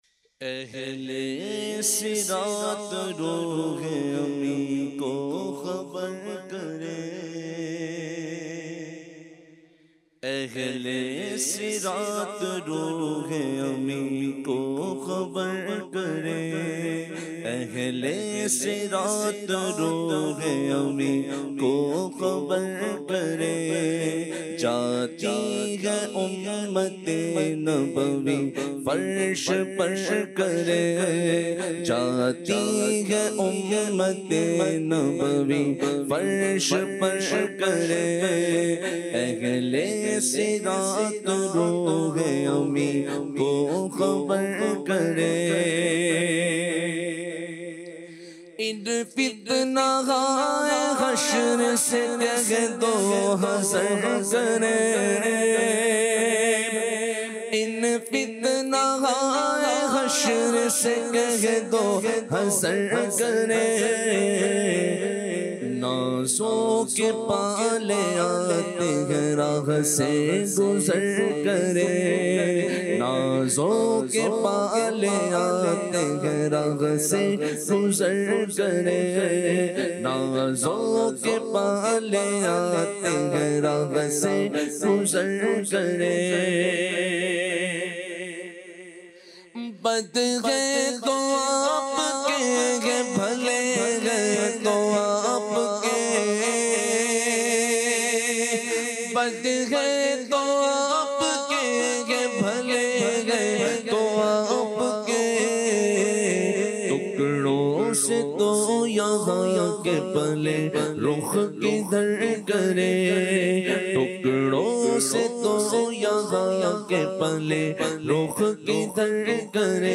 khutba
Vocalist